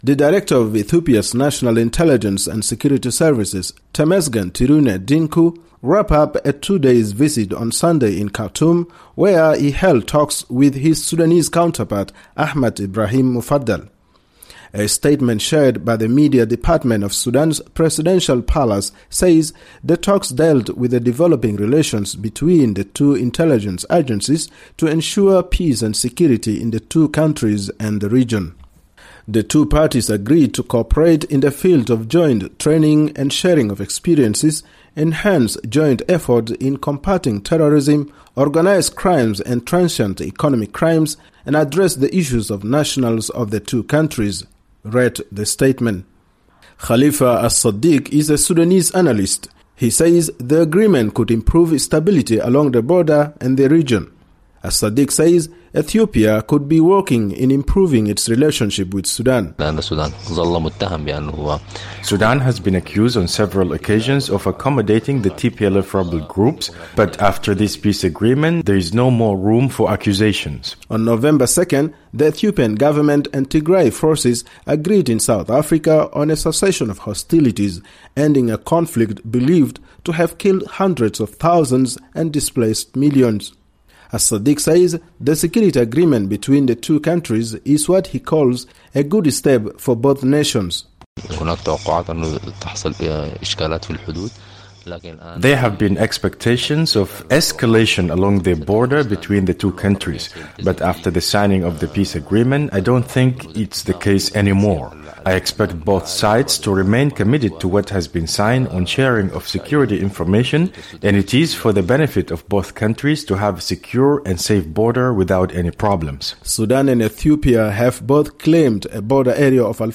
reports from Khartoum